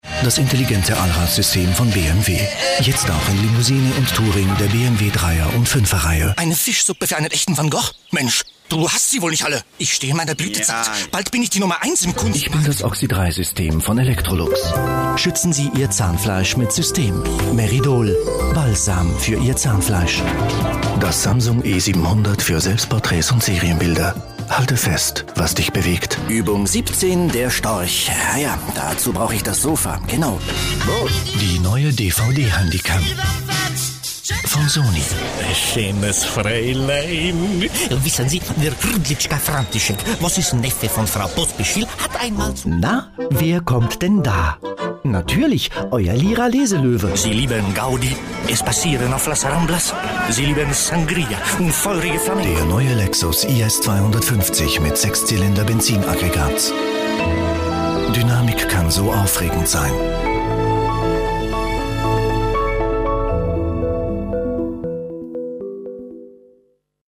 Flexible, erfahrene Stimme mit langjähriger Erfahrung im professionellen Sprecherbereich.